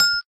xylophone_4.ogg